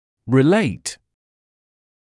[rɪ’leɪt][ри’лэйт](relate to) относить(-ся) к чему-то; соотносить, устанавливать связь